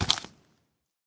sounds / mob / zombie / step3.ogg
step3.ogg